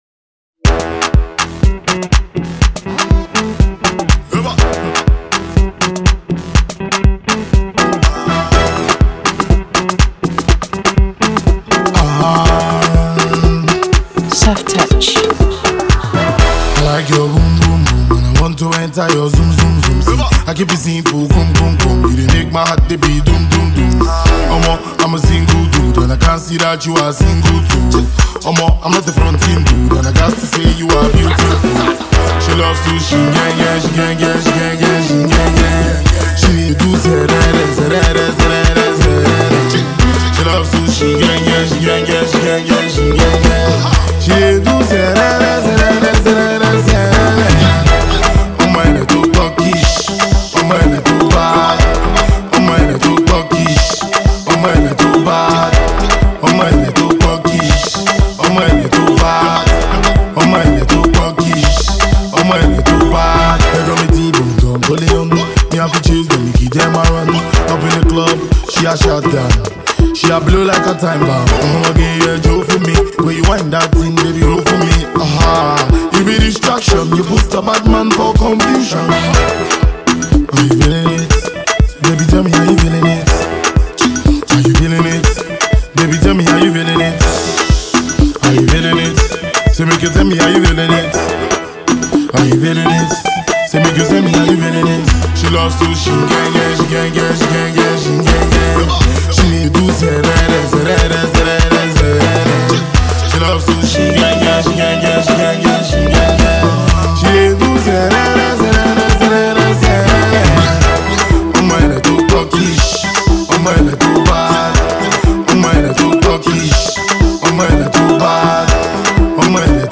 is a major club banger, a great melody